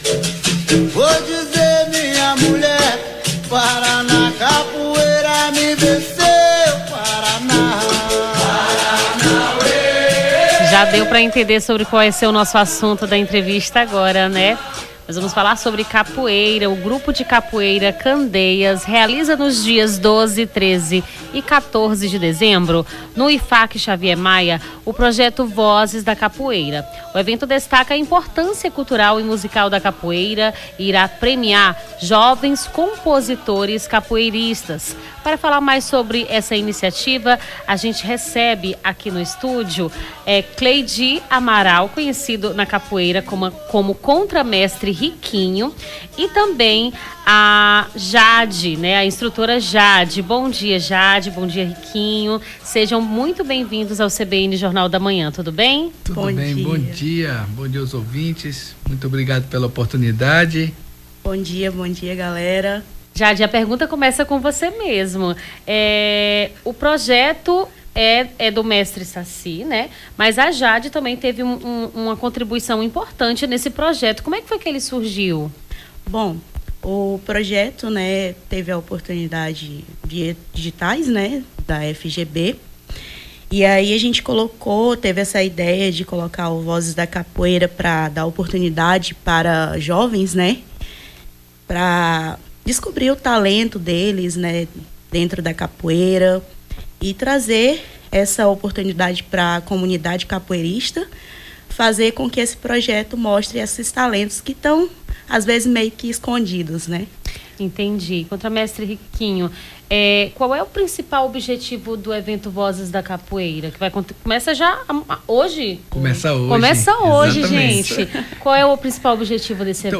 Nome do Artista - CENSURA - ENTREVISTA VOZES DA CAPOEIRA (12-12-24).mp3